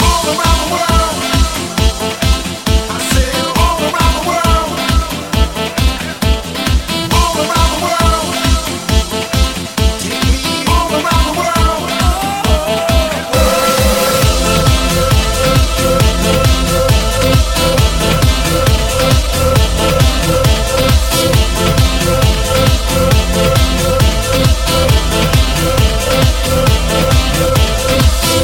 Genere: dance,disco,pop,house,afro,hit,remix